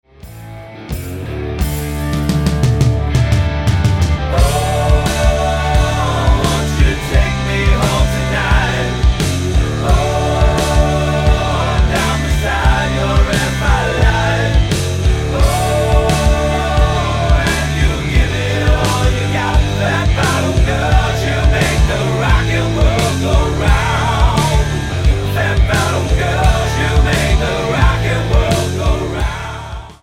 --> MP3 Demo abspielen...
Tonart:D mit Chor